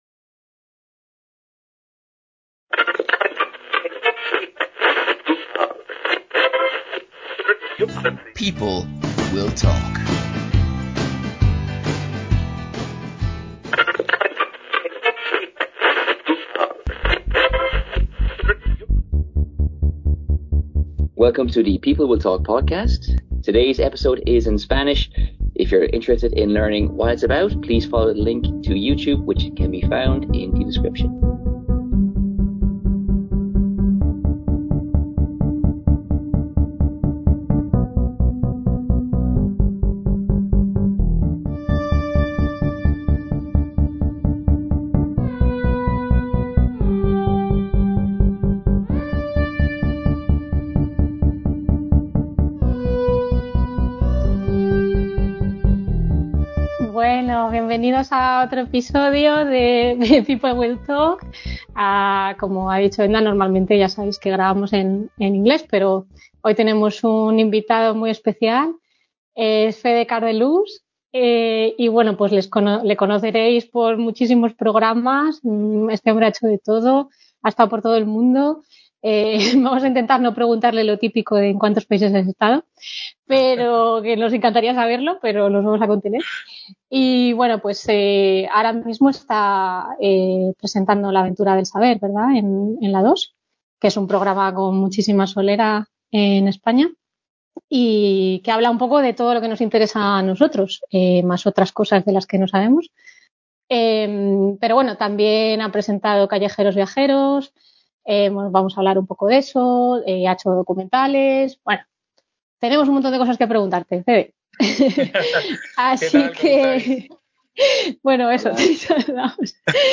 Un libro como equipaje: Entrevista con el periodista